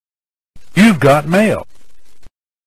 You Got Mail - Sound Effect for Editing.mp3